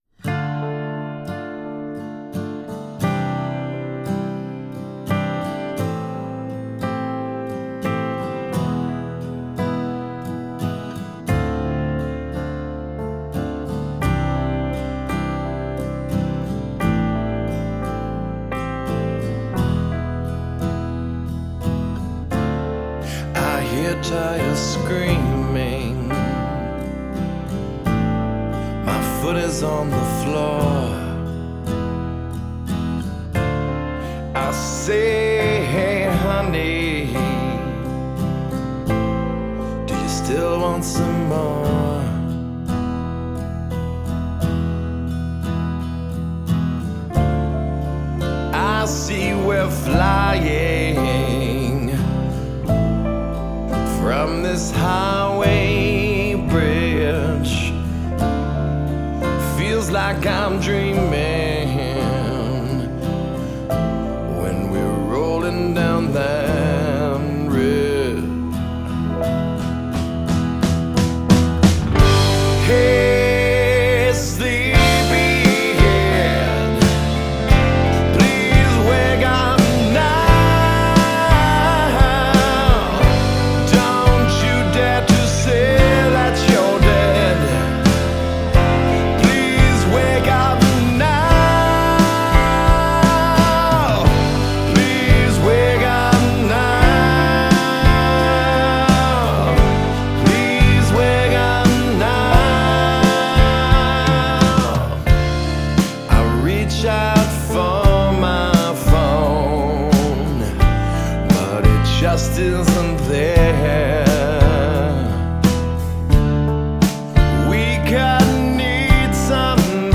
Get the “British Rock” Sound
in the style of “British Rock”